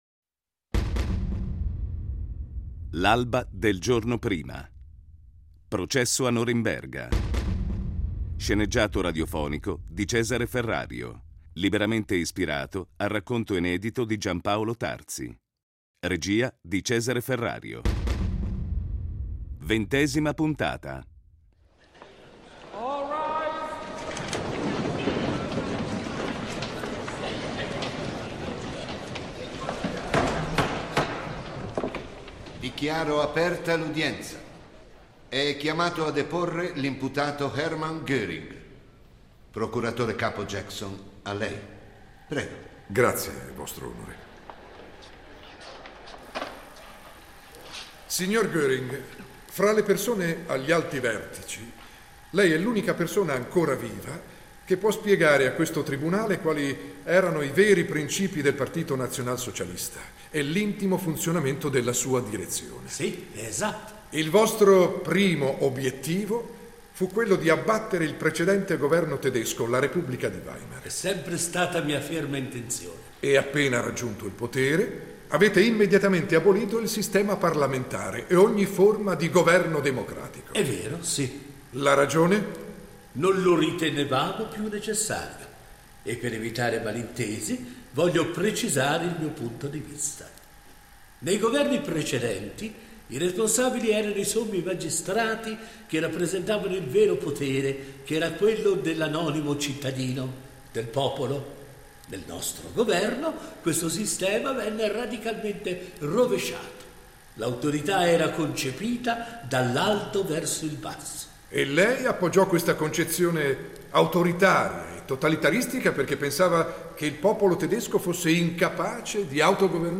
Oltre alle questioni storiche e di responsabilità personale, lo sceneggiato pone particolare attenzione agli accadimenti che influenzarono lo sviluppo del Diritto penale internazionale.